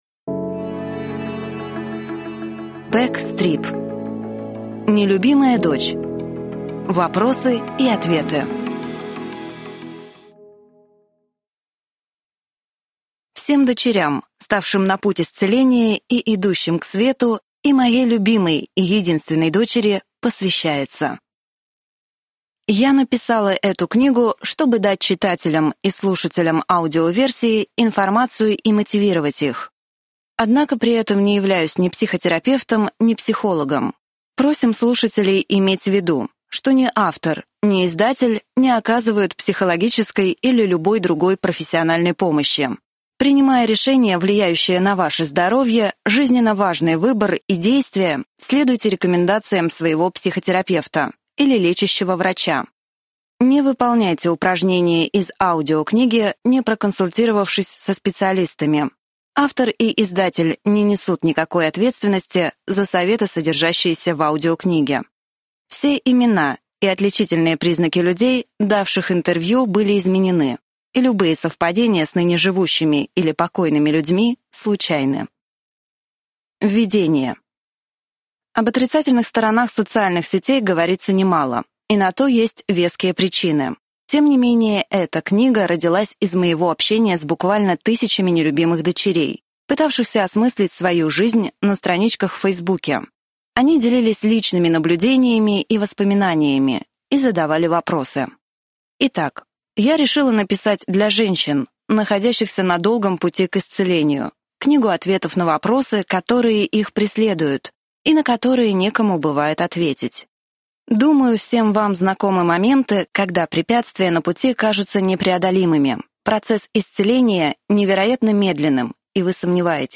Аудиокнига Нелюбимая дочь. Вопросы и ответы | Библиотека аудиокниг